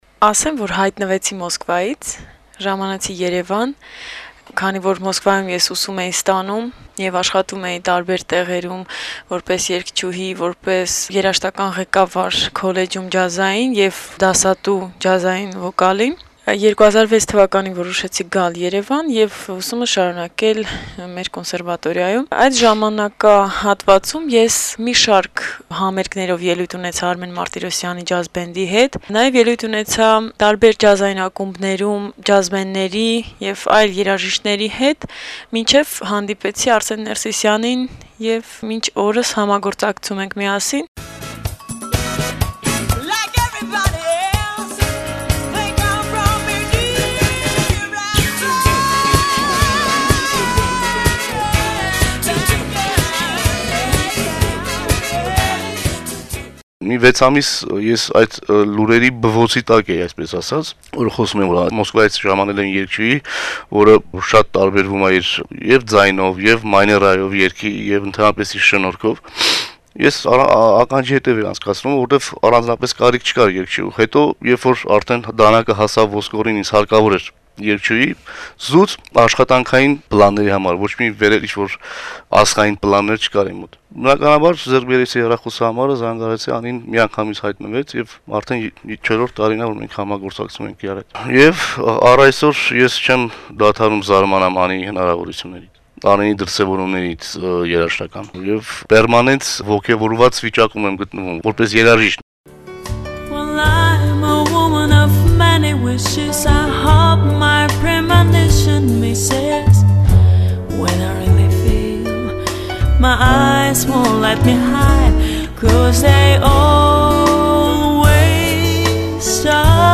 հարցազրույցը